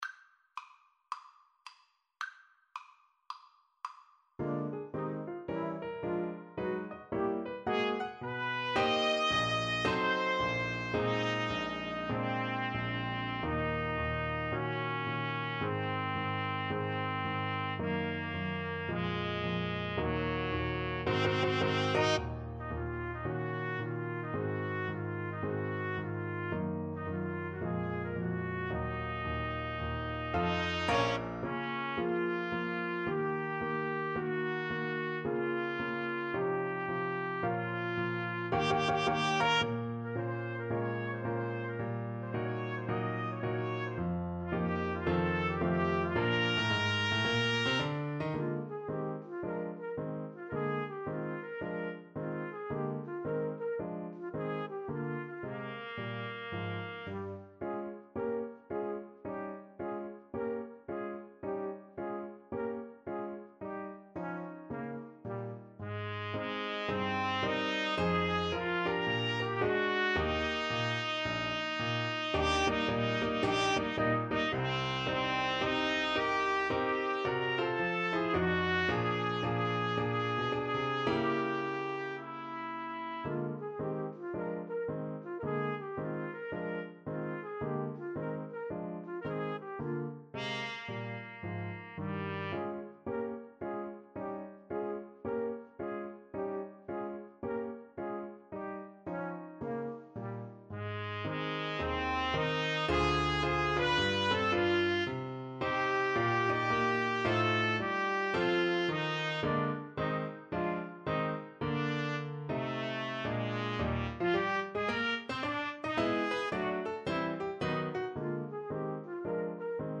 4/4 (View more 4/4 Music)
Moderato =110 swung